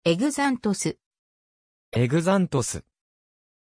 Pronunția numelui Xanthos
pronunciation-xanthos-ja.mp3